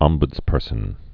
(ŏmbŭdz-pûrsən, -bədz-, -bdz-)